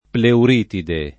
pleuritide [ pleur & tide ]